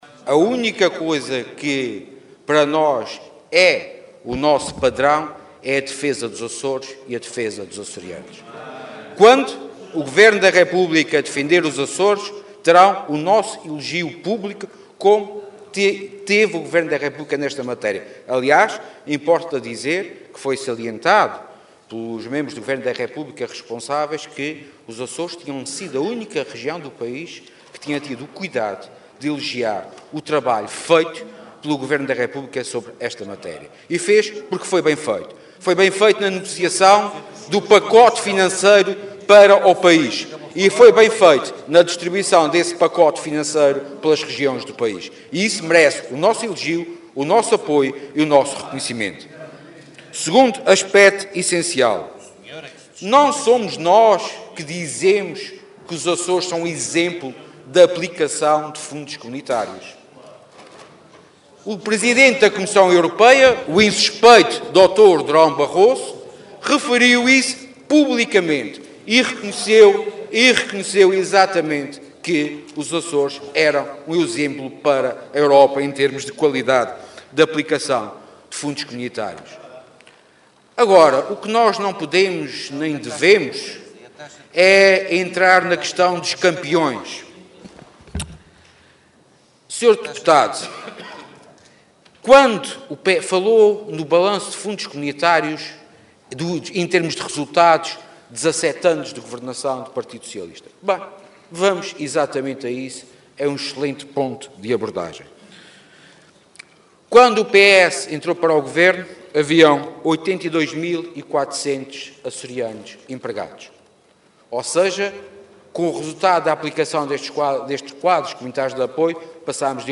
Sérgio Ávila, que falava na Assembleia Legislativa, salientou que aquela proposta contou com a colaboração ativa do Governo Regional, em nome do qual elogiou a forma como o Governo da República defendeu os interesses das regiões, acrescentando terem sido os Açores a única região a reconhecer esse bom trabalho.
O Vice-Presidente, que falava num debate suscitado por uma intervenção sobre os resultados da execução dos fundos dos quadros comunitários na última década e meia nos Açores, frisou que a Região evoluiu significativamente nesse período de tempo.